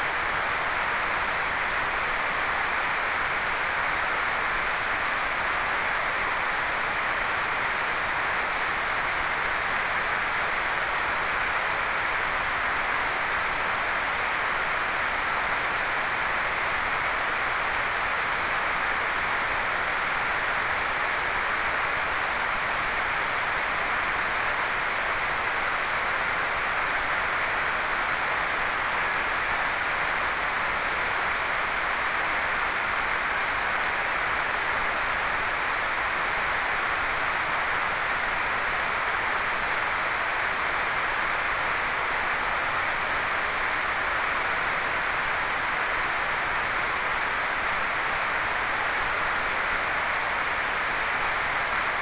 Prove di ascolto WSJT - 8,9 aprile 2011
Antenna: 16JXX, Apparato: FT897
nessun preamplificatore
N.B.: Solo nel primo file si può ascoltare un flebile "suono"... per tutti gli altri solo utilizzando WSJT si ha evidenza dei segnali ricevuti.